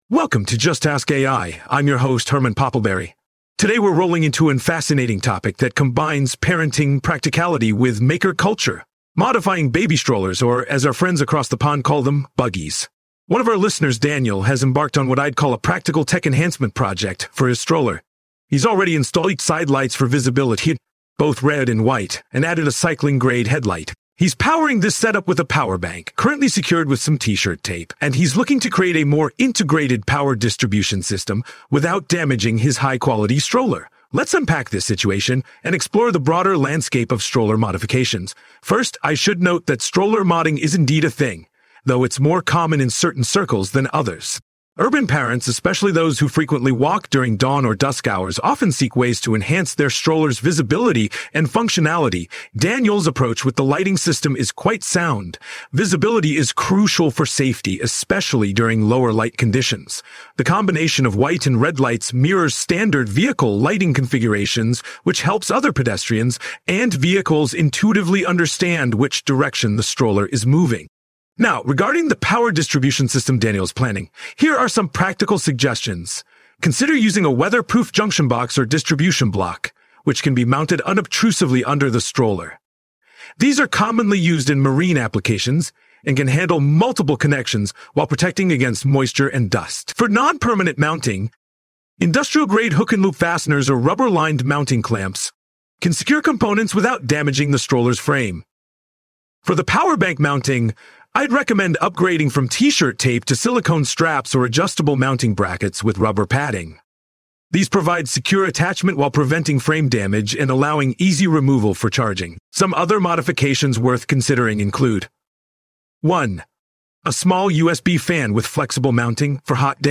AI-Generated Content: This podcast is created using AI personas. Please verify any important information independently.
Hosts Herman and Corn are AI personalities.